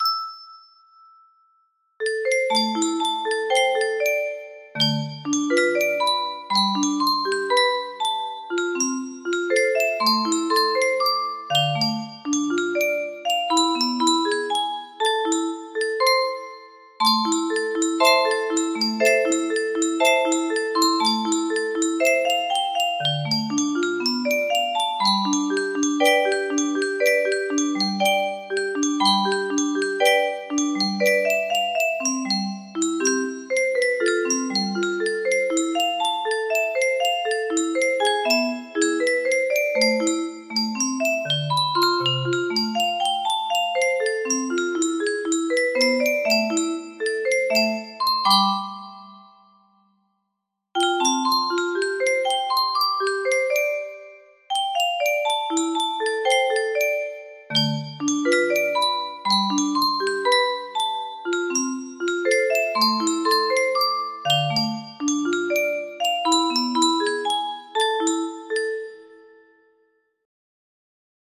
Tempo 60-80